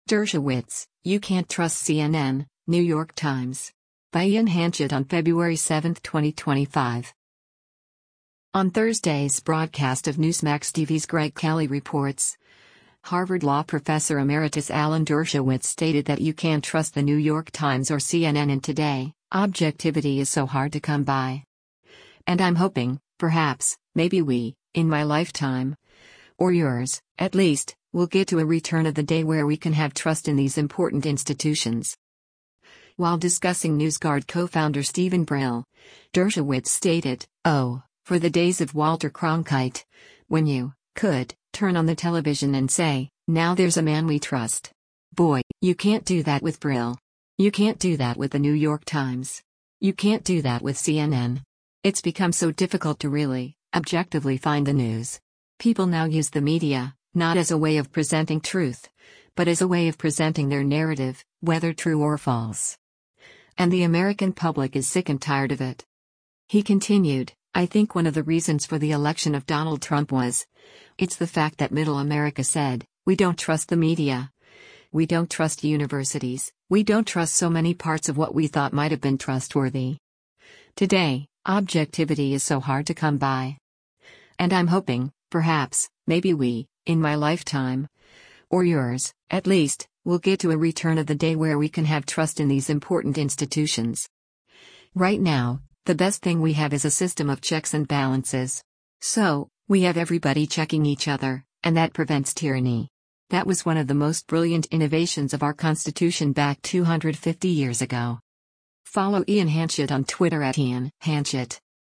On Thursday’s broadcast of Newsmax TV’s “Greg Kelly Reports,” Harvard Law Professor Emeritus Alan Dershowitz stated that you can’t trust The New York Times or CNN and “Today, objectivity is so hard to come by. And I’m hoping, perhaps, maybe we, in my lifetime, or yours, at least, will get to a return of the day where we can have trust in these important institutions.”